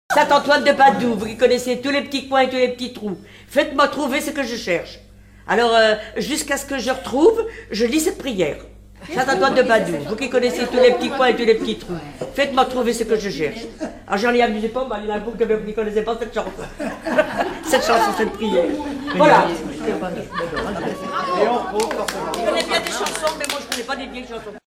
prière(s)
Regroupement de chanteurs du canton
Pièce musicale inédite